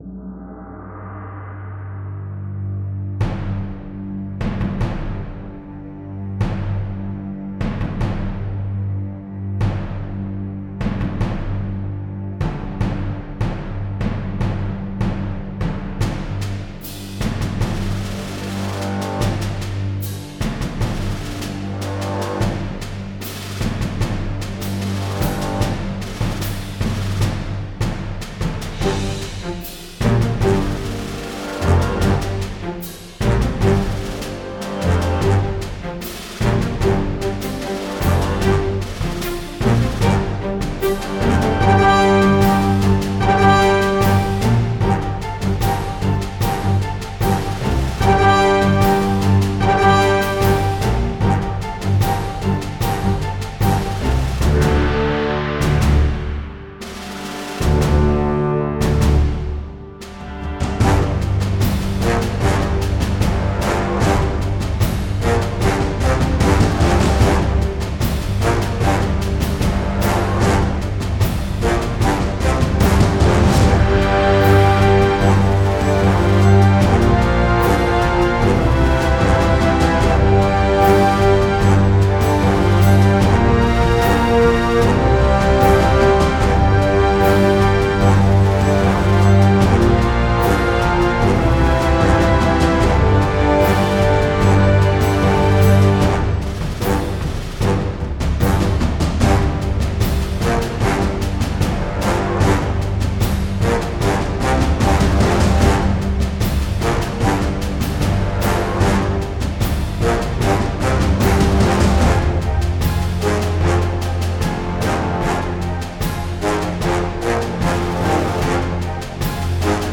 это атмосферная композиция в жанре инди-фолк